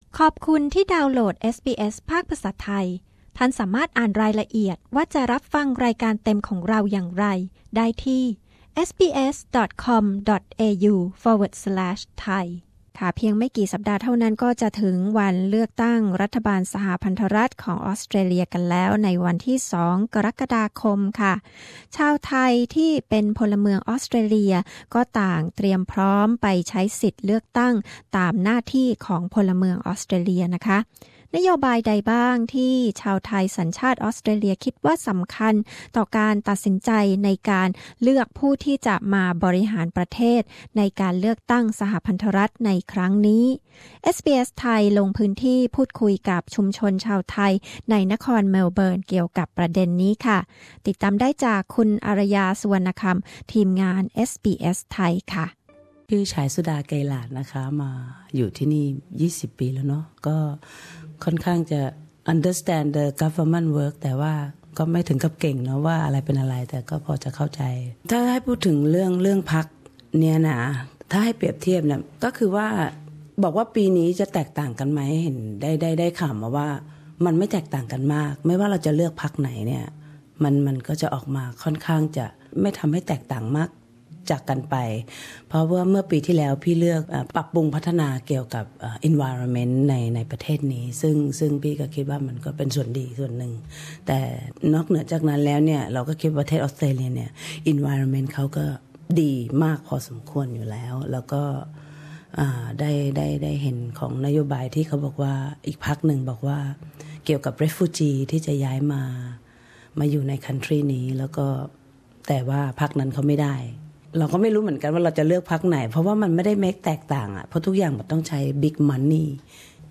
นโยบายใดบ้างที่ชาวไทยสัญชาติออสเตรเลียคิดว่าสำคัญต่อการตัดสินใจในการเลือกผู้ที่จะมาบริหารประเทศในการเลือกตั้งสหพันธรัฐในวันเสาร์ที่ 2 กรกฎาคมนี้ เอสบีเอสไทย ลงพื้นที่พูดคุยกับชุมชนชาวไทย ในนครเมลเบิร์น เกี่ยวกับประเด็นนี้